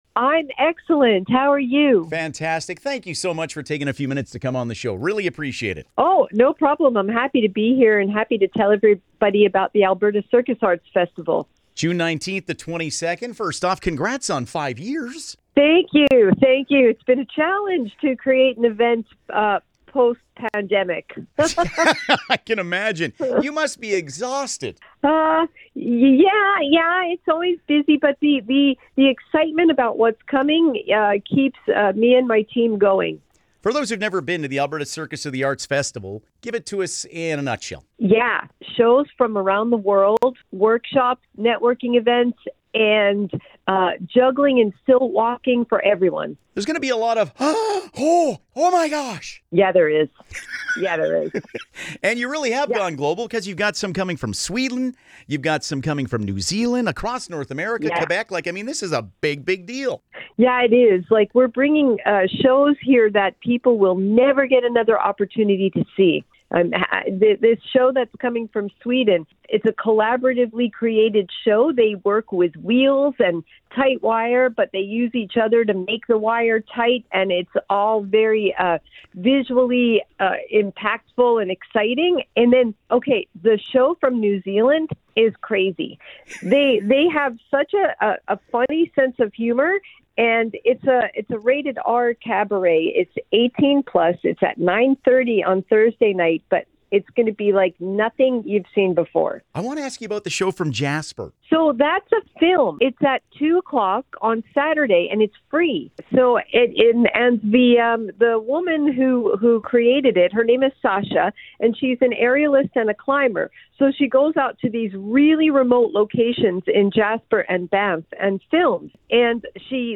ab-circus-arts-fest-int.mp3